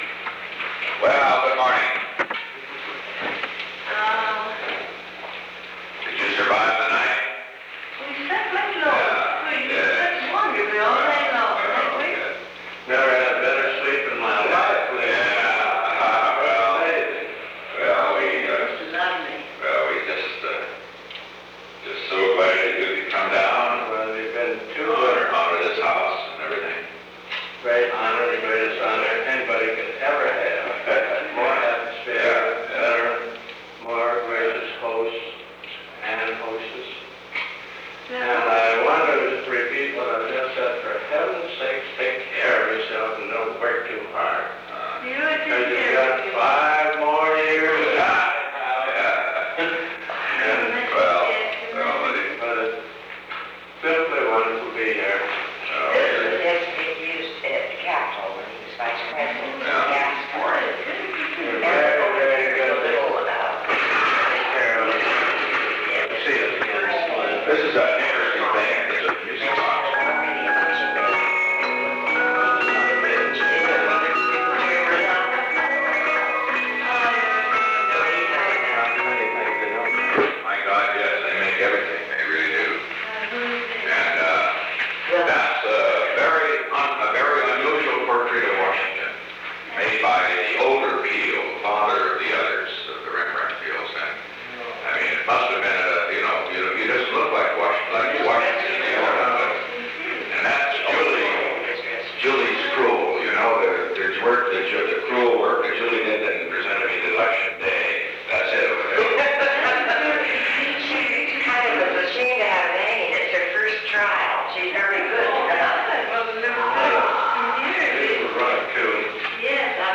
On January 29, 1972, President Richard M. Nixon, Thelma C. ("Pat") (Ryan) Nixon, DeWitt Wallace, and Lila (Acheson) Wallace met in the Oval Office of the White House from 9:00 am to 9:04 am. The Oval Office taping system captured this recording, which is known as Conversation 660-007 of the White House Tapes.